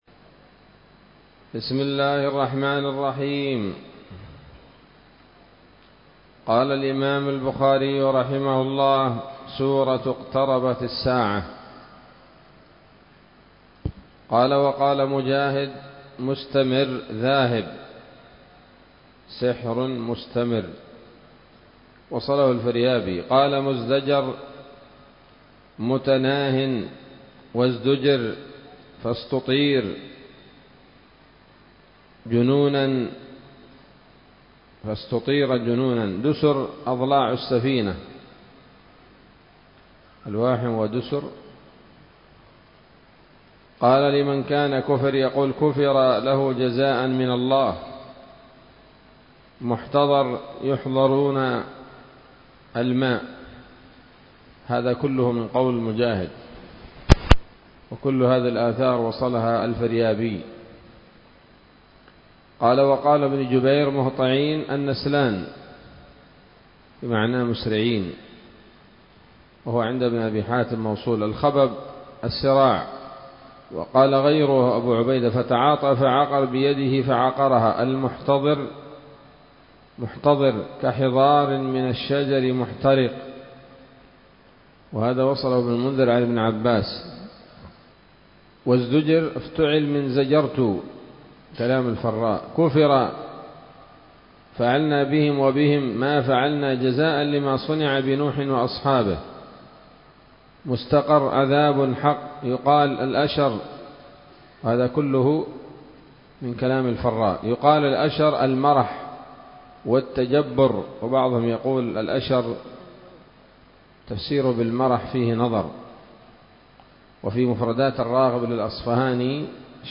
الدرس السادس والأربعون بعد المائتين من كتاب التفسير من صحيح الإمام البخاري